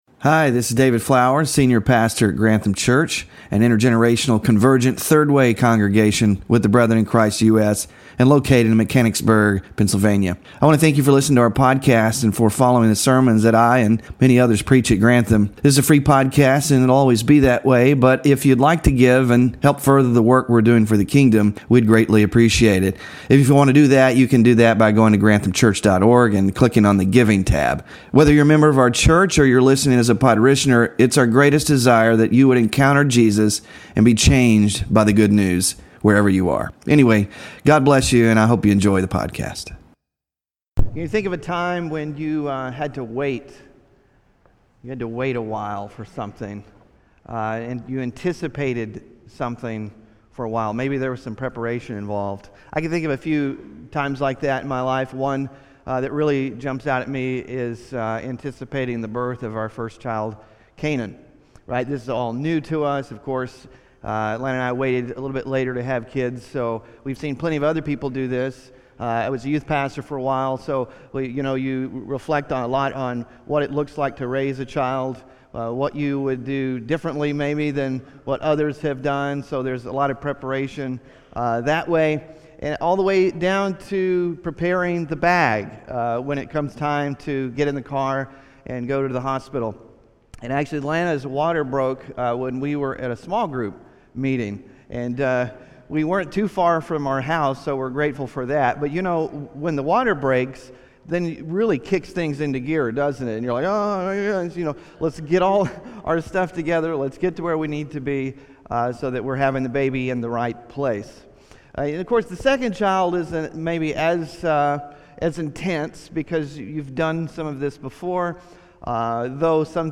Scripture Reading: 2 Chronicles 29; Isaiah 40:1-5; Malachi 3:1; Matthew 3:1-6